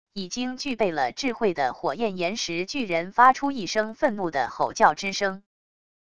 已经具备了智慧的火焰岩石巨人发出一声愤怒的吼叫之声wav音频